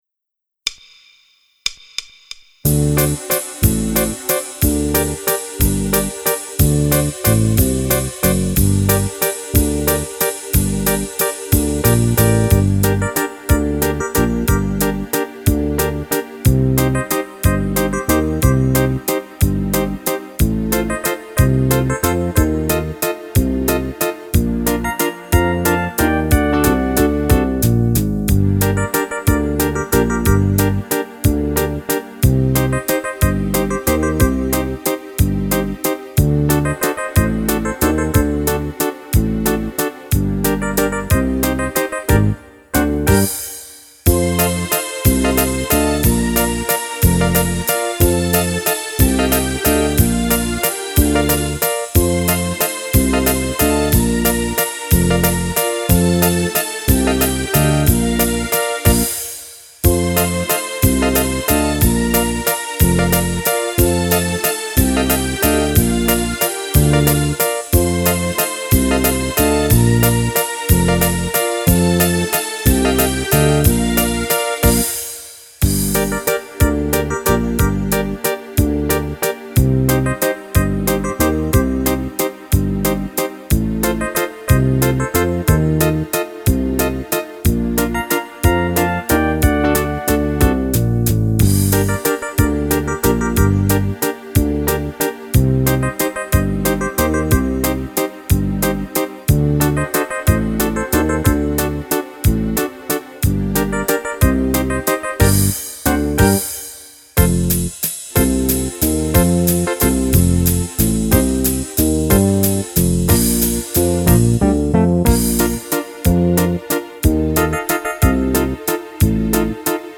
Valzer viennese
Fisarmonica